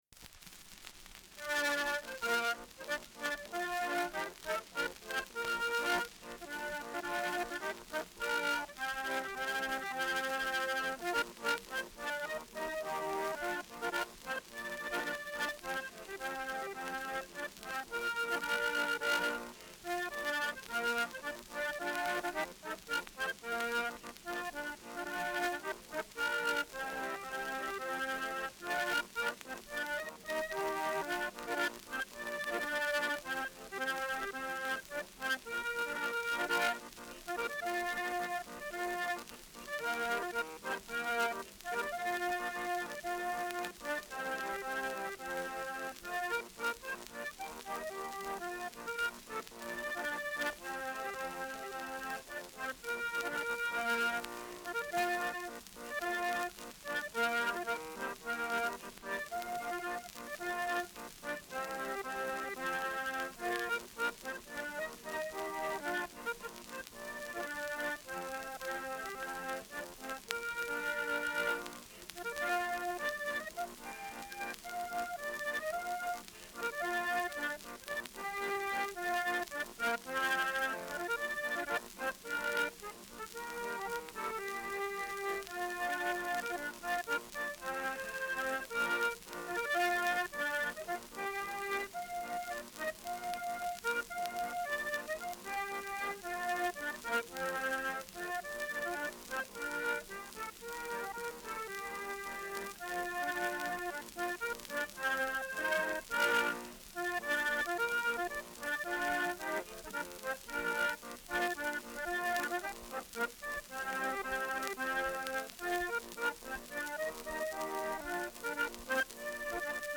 Trekkspill